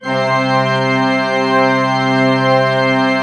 Index of /90_sSampleCDs/Propeller Island - Cathedral Organ/Partition F/PED.V.WERK M